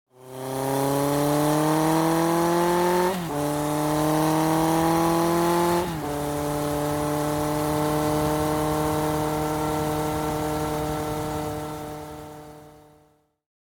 Sports Bike Driving, Accelerating, Engine Perspective Sound Effect Download | Gfx Sounds
Sports-bike-driving-accelerating-engine-perspective.mp3